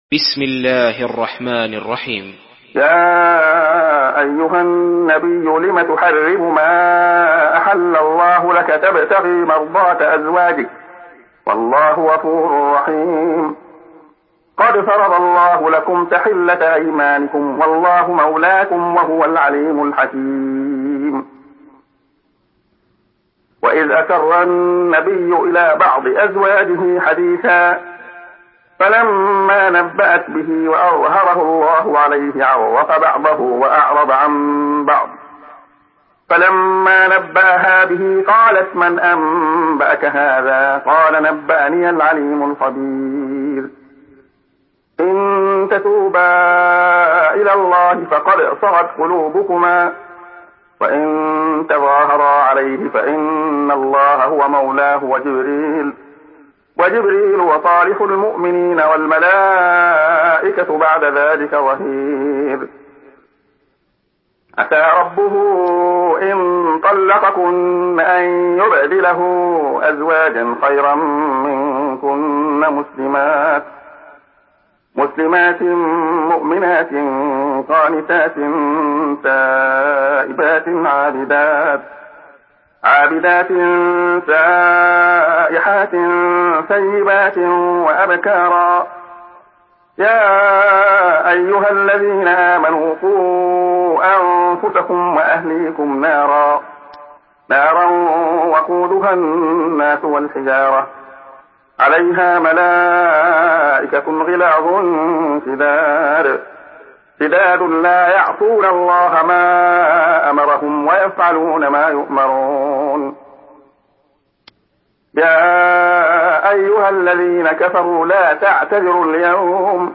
Surah Tahrim MP3 in the Voice of Abdullah Khayyat in Hafs Narration
Murattal Hafs An Asim